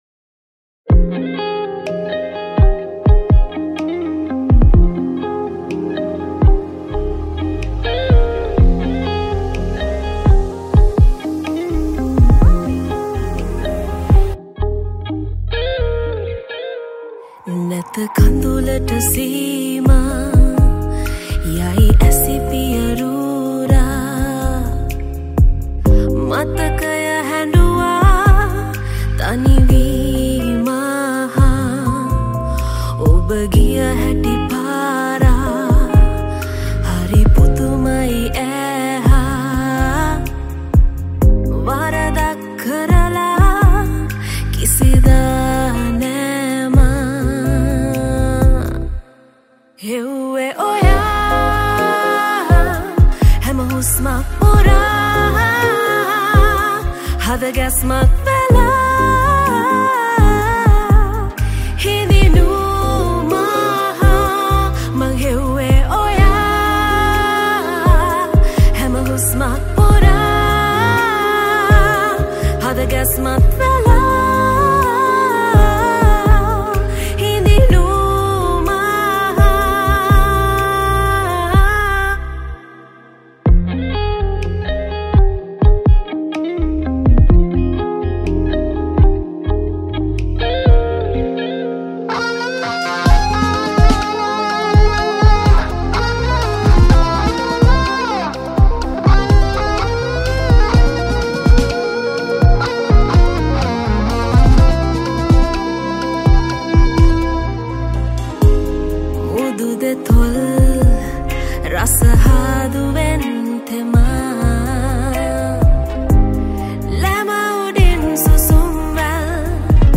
Guitars
Piano & Synths